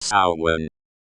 [↑] Note that Samhain is not to be pronounced as if it were an English word.
ˈsaʊ̯.wən/.